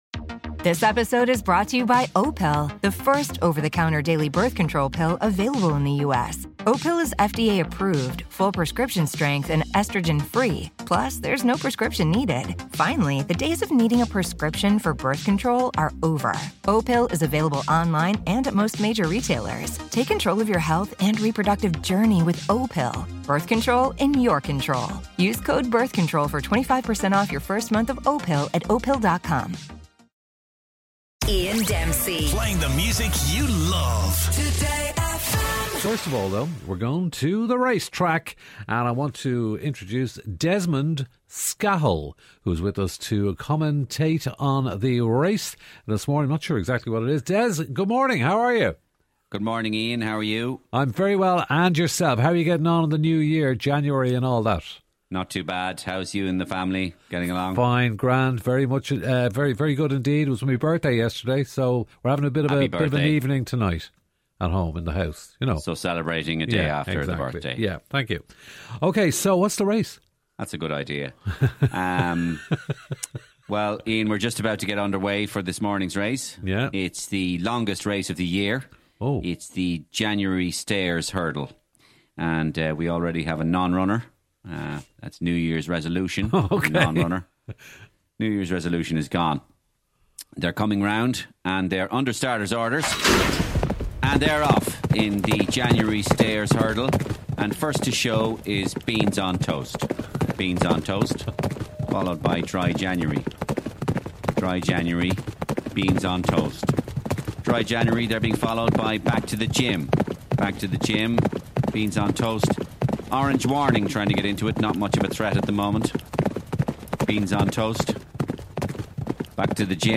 Comedy Ireland Today FM Morgan Freeman
gift_january_horse_race__73cd311b_normal.mp3